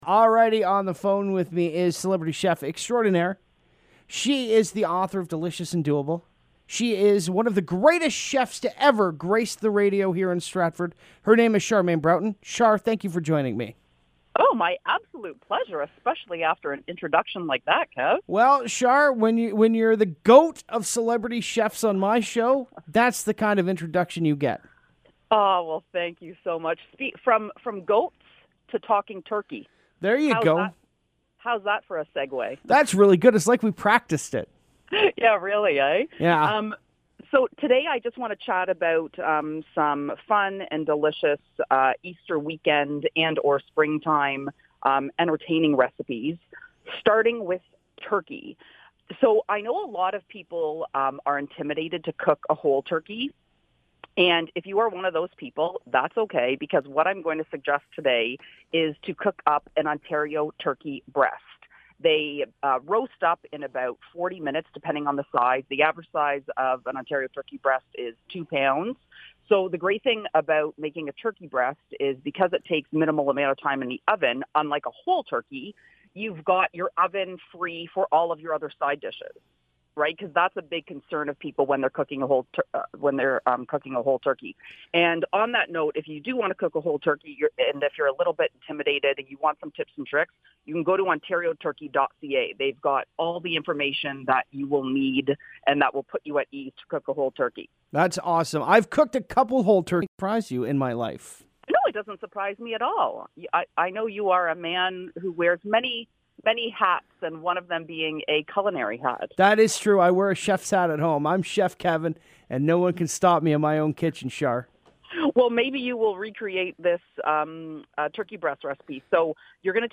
Here is the whole interview!!